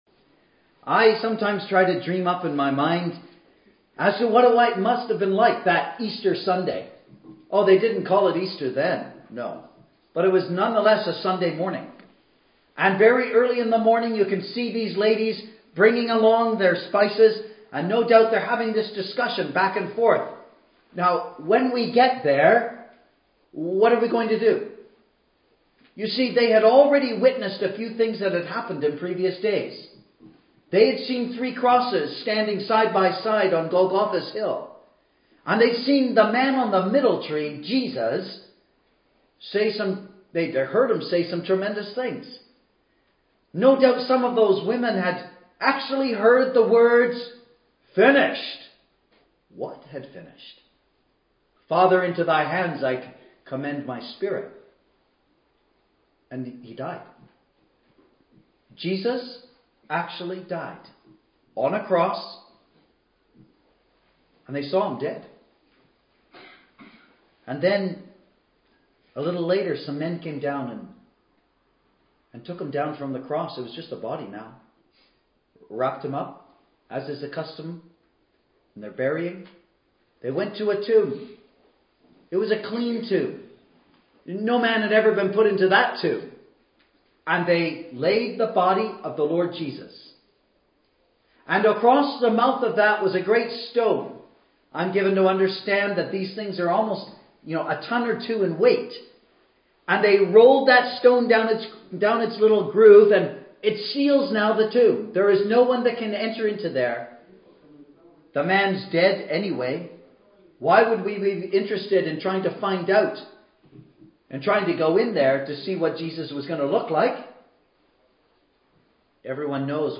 Easter Conference 2012 – GOSPEL